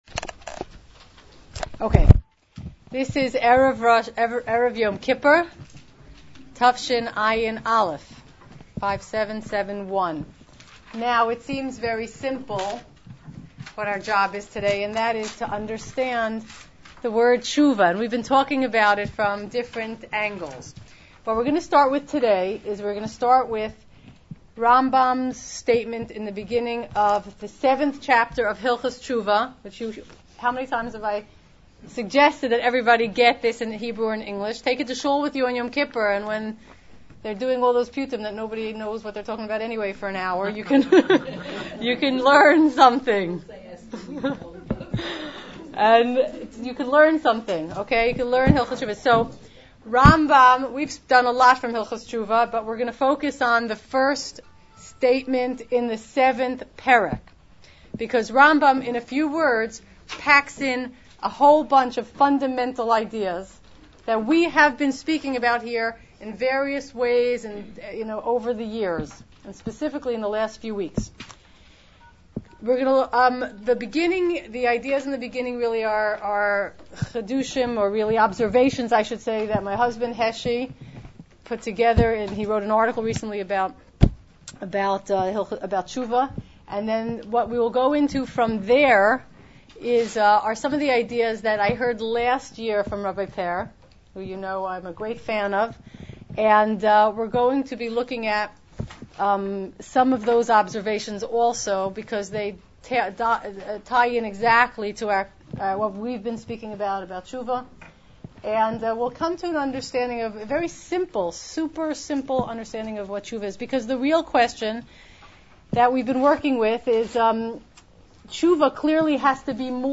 Yom Kippur 5771: Teshuva - Becoming Yashar Again